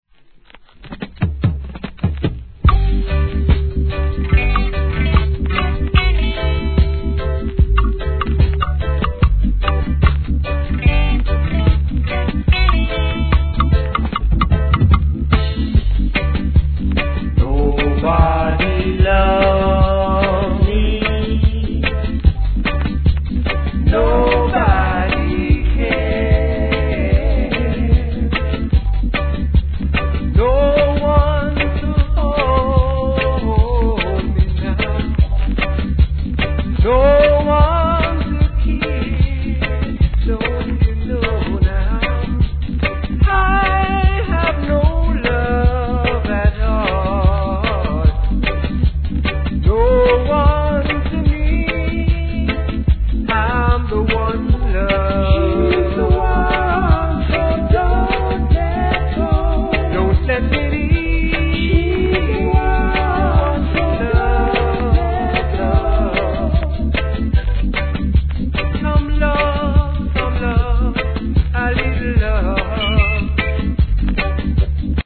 REGGAE
GOODヴォーカル!!